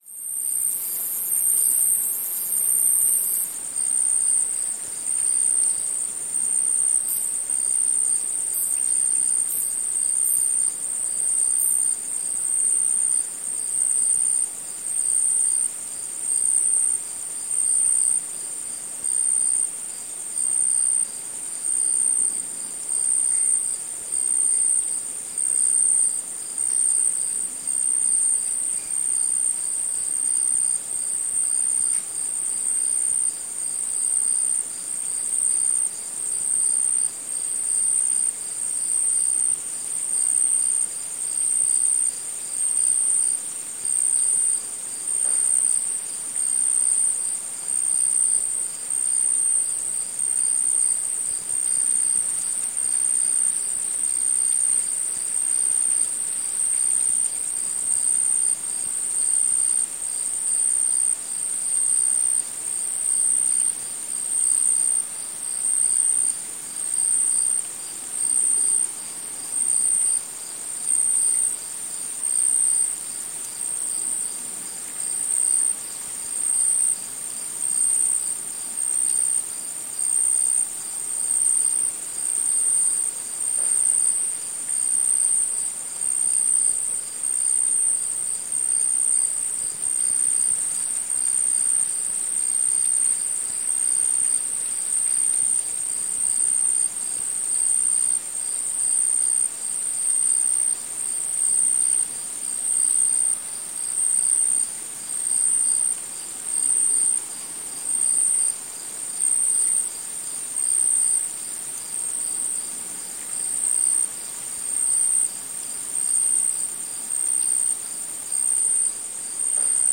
Шум насекомых в тропическом лесу